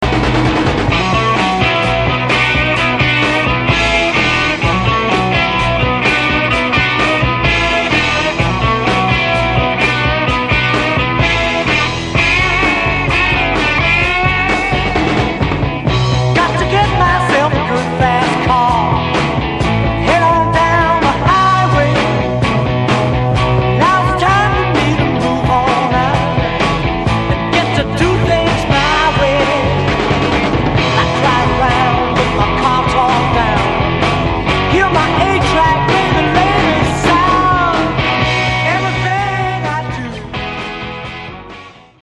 ドラム
ギター
ベース